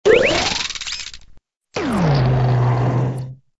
audio: Converted sound effects
SA_evil_eye.ogg